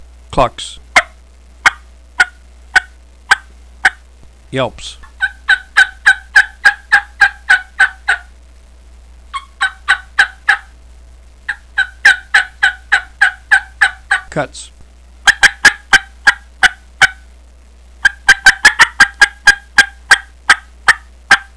Listen to 21 seconds of clucks, yelps, & cutts
• Makes good higher-to-moderate-pitched raspy yelps, cackles, clucks and cutts at all volume levels